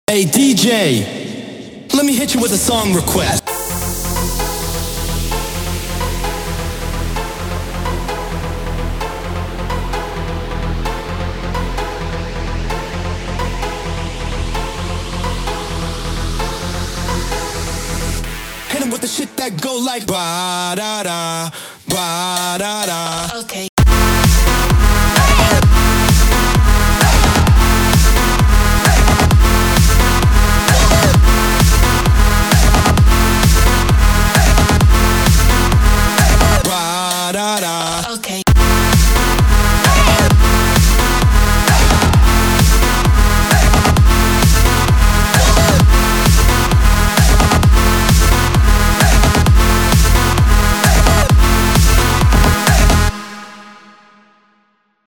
מקצבים לPA 700